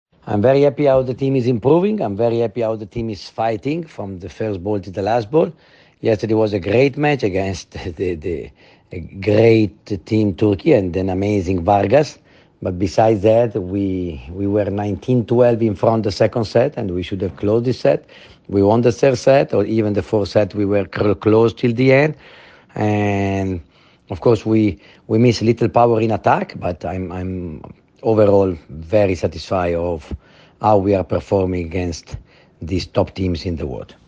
Izjava Đovanija Gvidetija